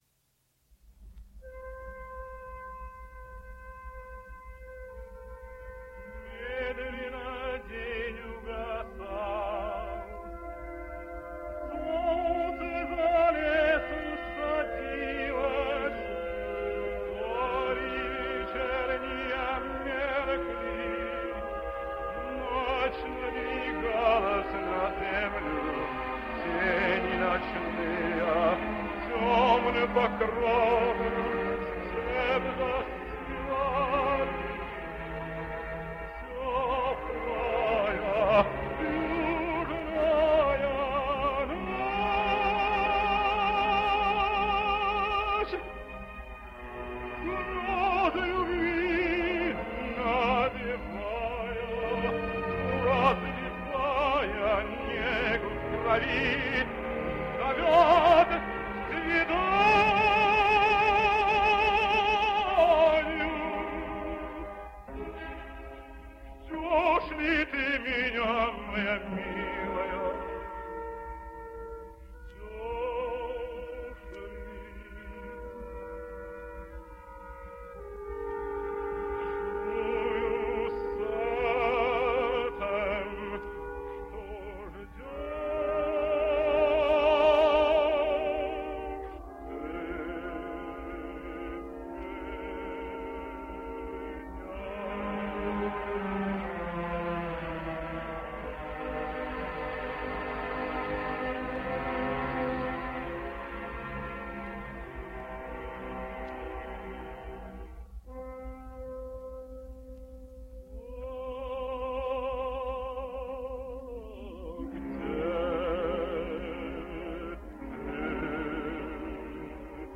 Russian Tenor.
Here he is in an Aria from Borodin’s Prince Igor.